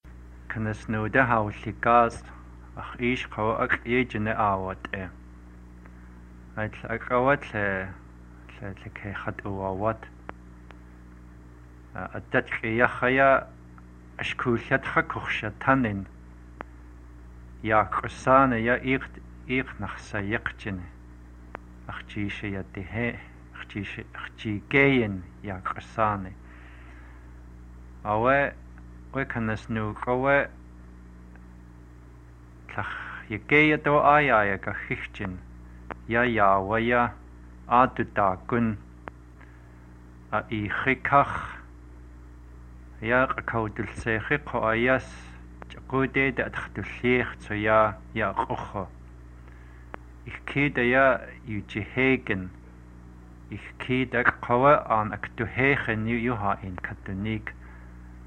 26 February 2012 at 3:30 pm I hear voiceless lateral affricates, as well as ejectives.